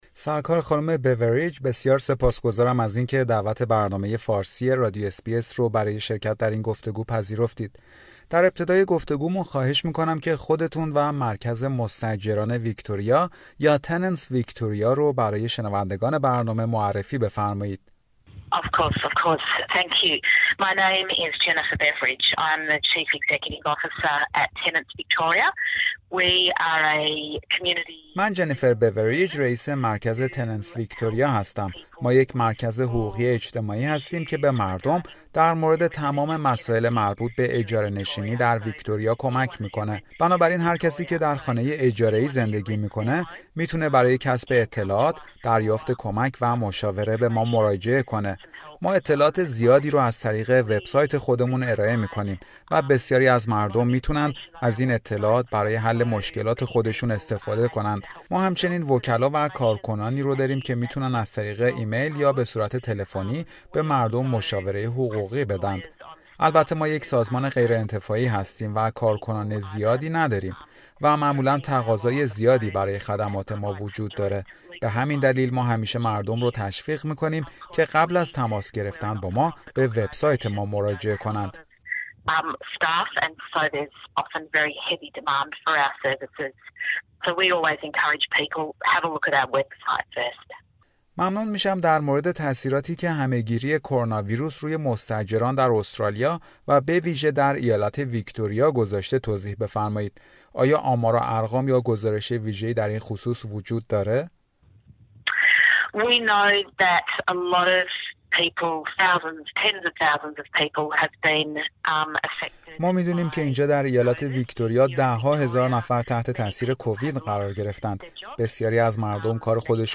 گفتگویی اختصاصی درباره شرایط دسترسی به حمایت مالی تا ۳ هزار دلاری برای مستاجران در ویکتوریا